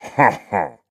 Minecraft Version Minecraft Version snapshot Latest Release | Latest Snapshot snapshot / assets / minecraft / sounds / mob / evocation_illager / celebrate.ogg Compare With Compare With Latest Release | Latest Snapshot
celebrate.ogg